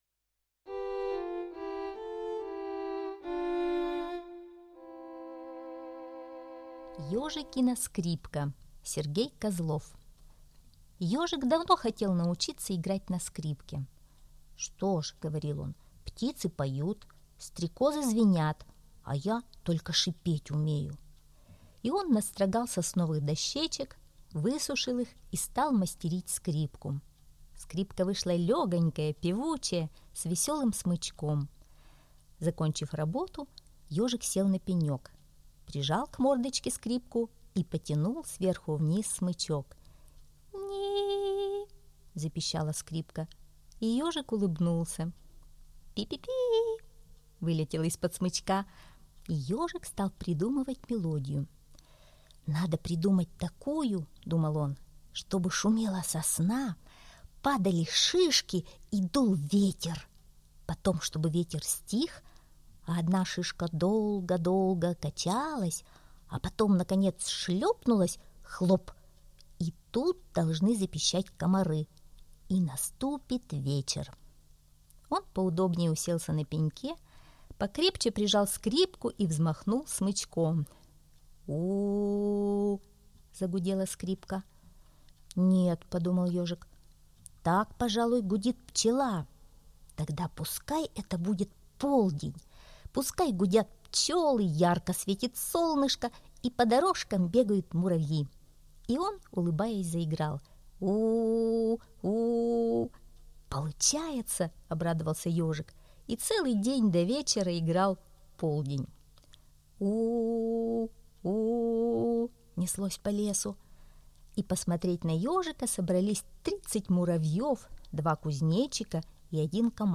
Ежикина скрипка - аудиосказка Козлова С.Г. Однажды Ежик сделал себе скрипку.